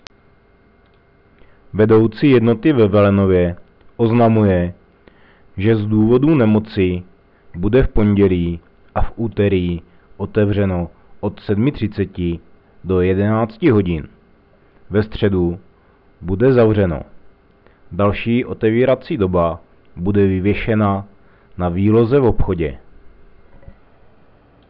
del – Hlášení rozhlasu – 4. stránka – Obec Velenov